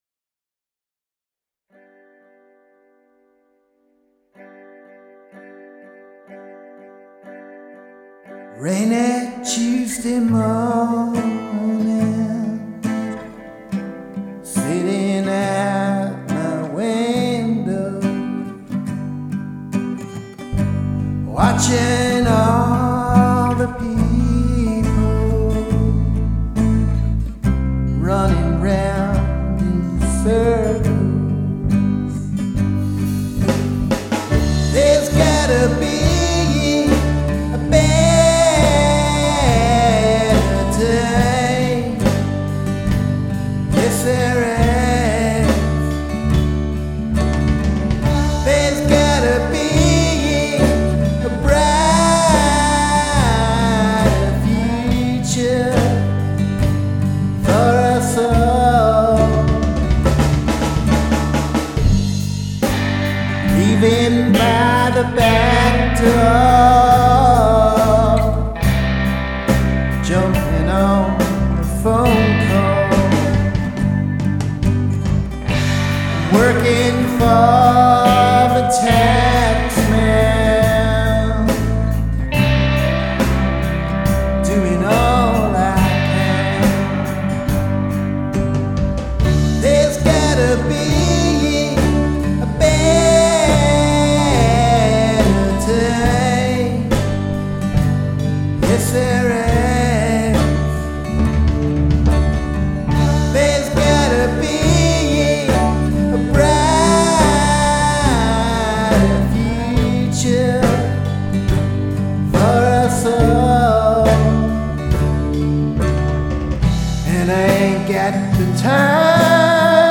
dance/electronic
Folk
Country-rock